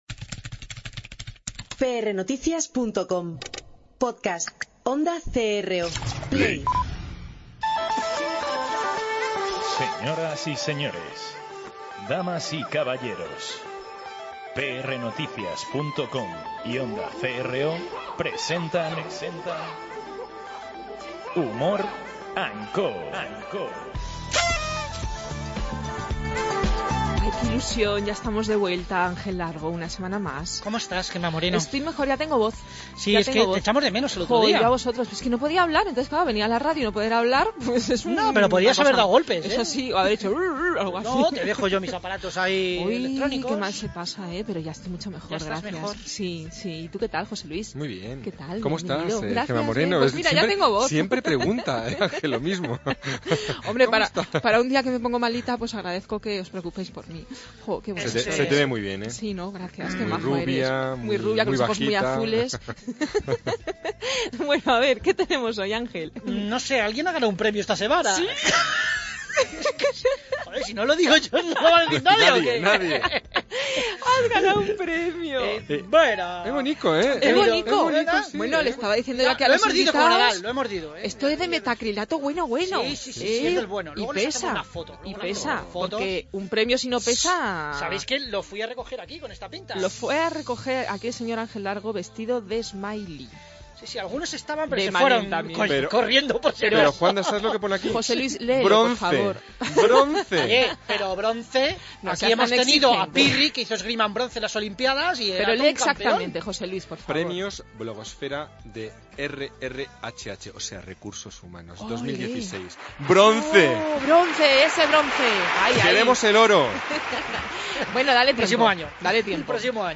En el Consultorio un experto Laboralista nos hablara de cuáles son los casos en los que podemos ser despedidos por infracciones en el trabajo o como debemos actuar en casos de Acoso.